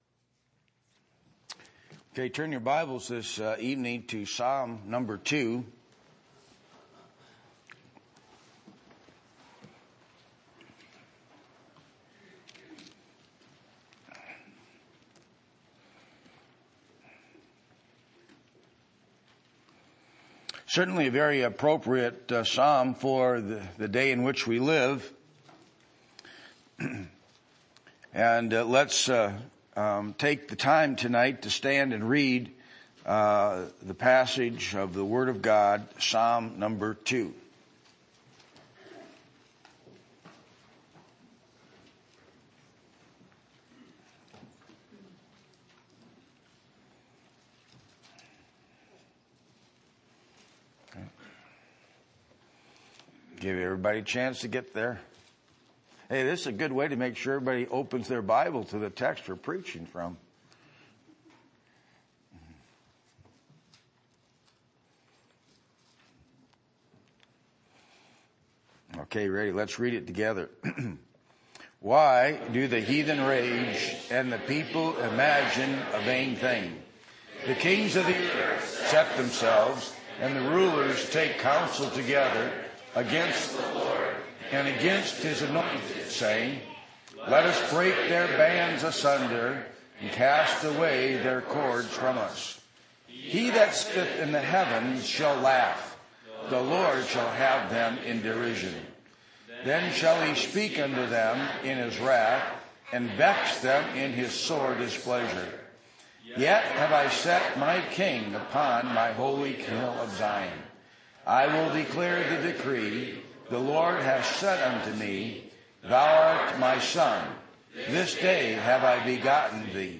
Psalm 2:1-12 Service Type: Sunday Evening %todo_render% « Special Angelic Deliverance of God’s People What Does it Mean to Honor Our Parents?